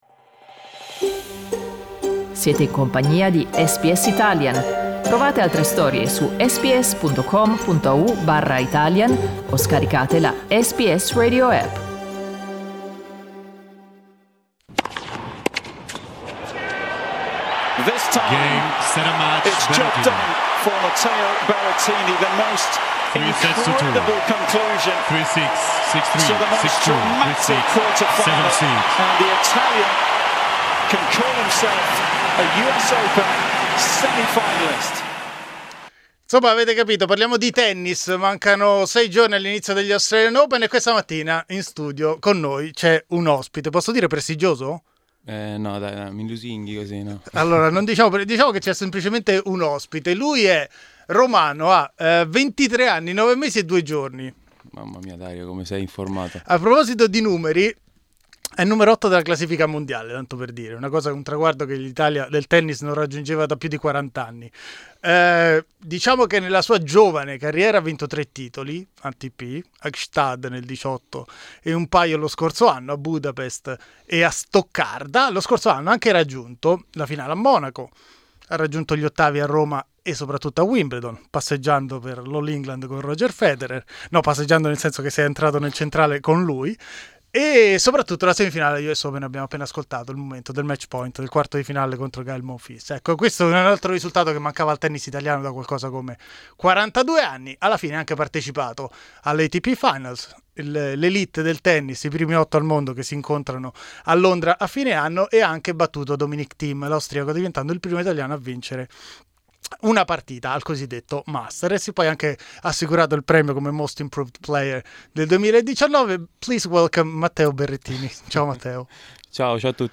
L'ottavo tennista della classifica mondiale è stato ospite nei nostri studi di Melbourne a 6 giorni dall'inizio degli Australian Open.